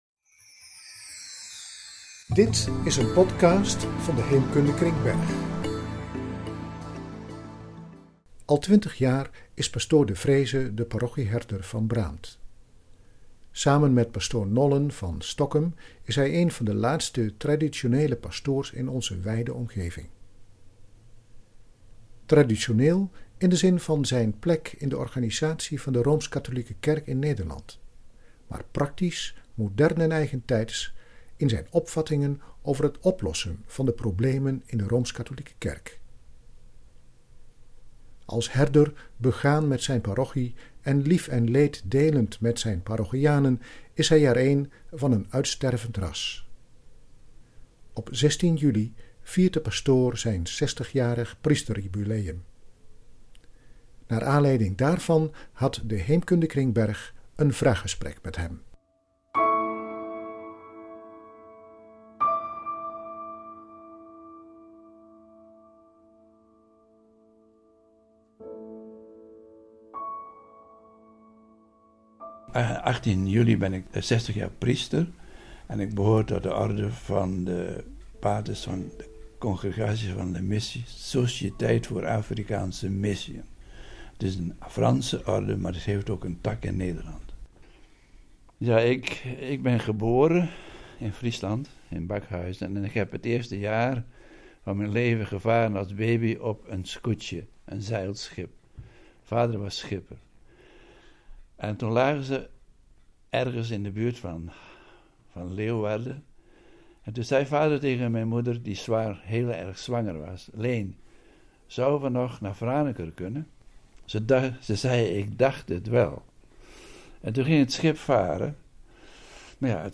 Podcast interview
vraaggesprek